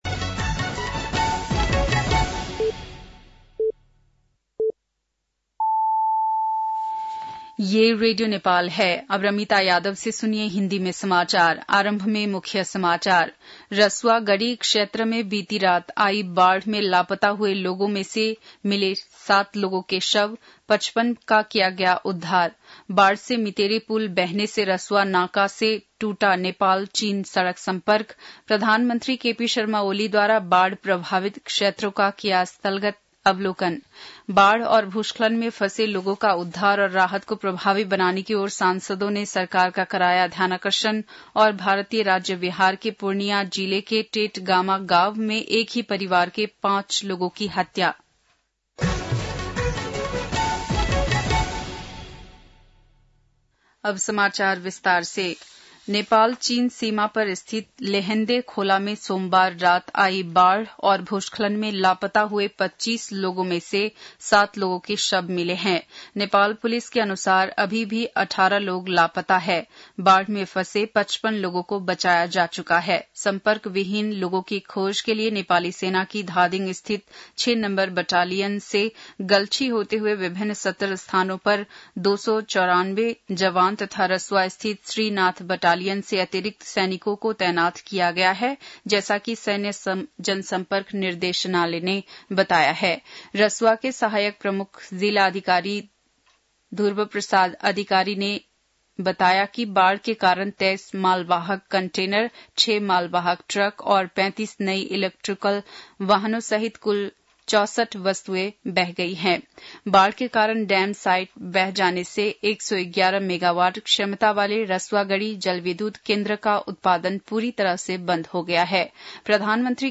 बेलुकी १० बजेको हिन्दी समाचार : २४ असार , २०८२
10-pm-hindi-news-3-24.mp3